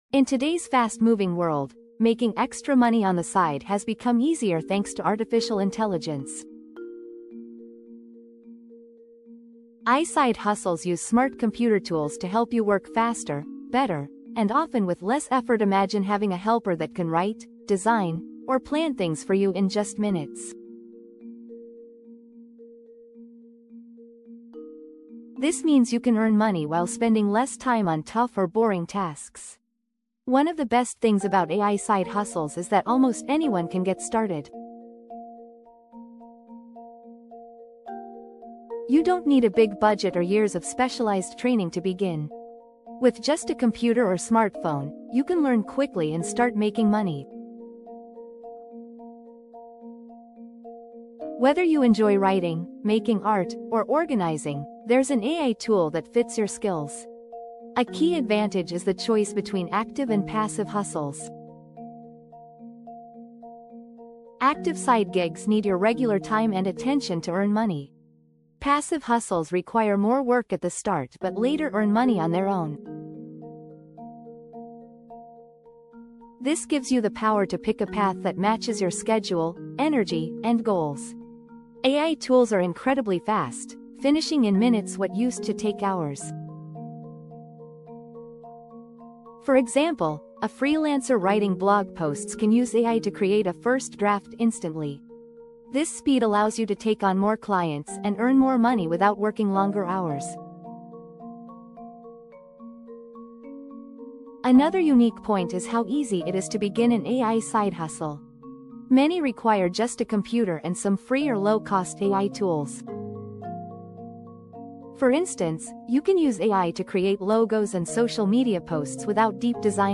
Lesson Audio